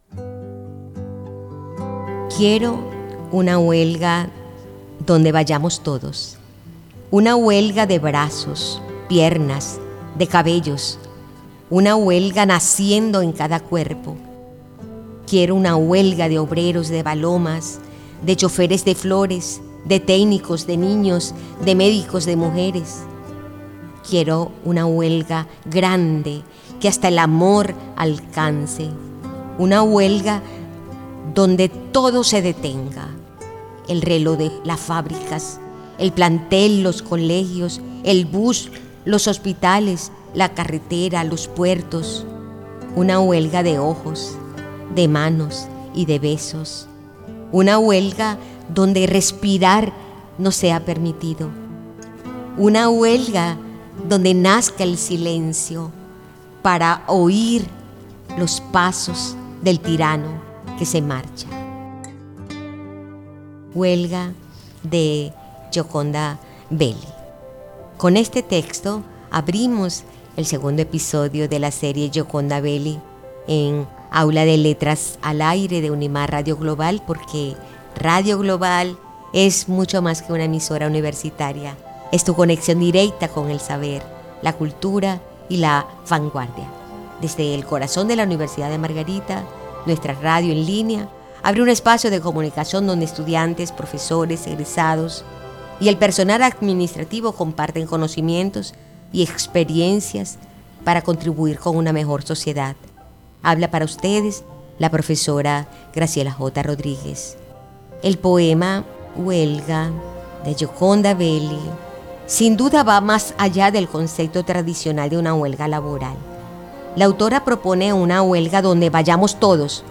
Aula de Letras al Aire es un programa de radio universitaria que lleva la literatura (poemas y relatos) más allá del aula, ofreciendo una experiencia auditiva y accesible para la comunidad universitaria y el público en general.